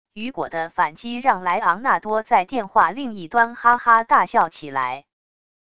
雨果的反击让莱昂纳多在电话另一端哈哈大笑起来wav音频生成系统WAV Audio Player